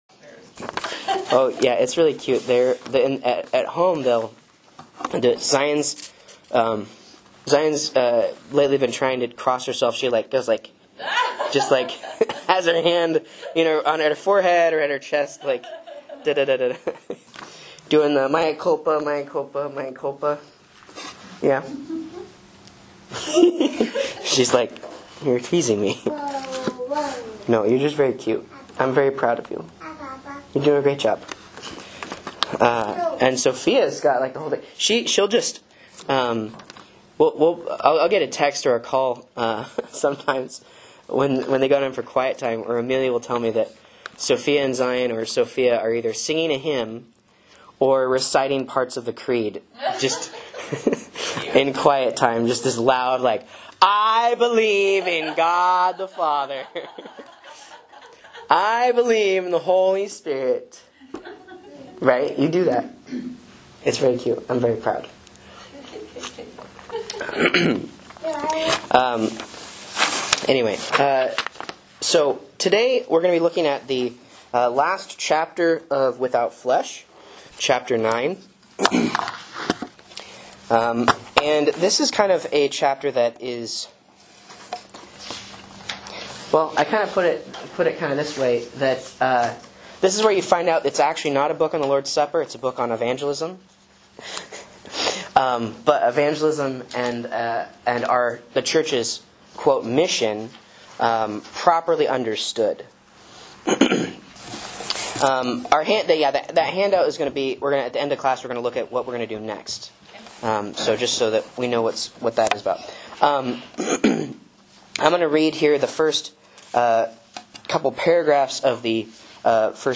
Sermons and Lessons from Faith Lutheran Church, Rogue River, OR
A Sunday School Class on Fisk, "Without Flesh," Chapter 9-Close